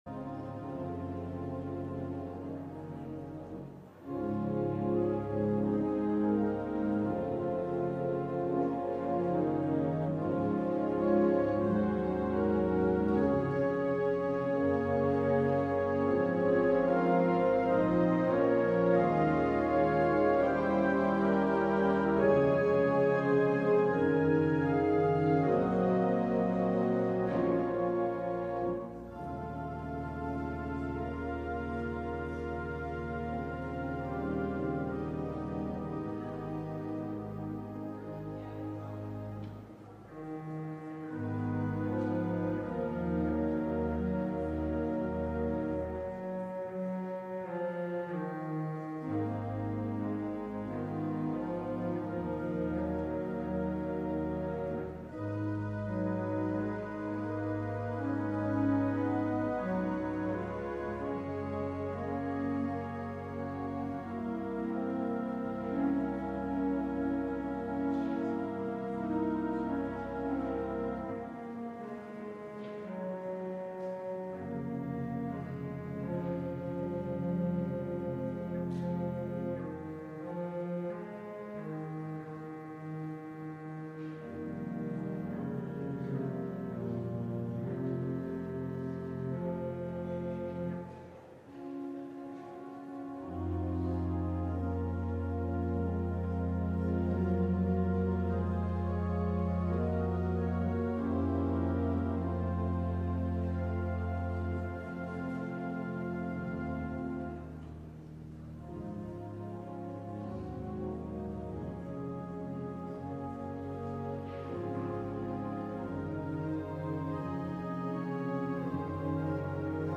LIVE Midday Worship Service - The Image of the Invisible God: Trust And Obey